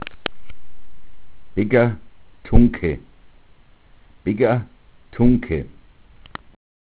Stoffsammlung: Mundart: begga_tuncke Quelle Felix Mader, Die Kunstdenkmäler von Eichstätt Das Spitaltor an der Altmühlbrücke bestand aus einem quadratischen mittelalterlichen Turm.